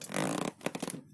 Crossbow_StringPull 03.wav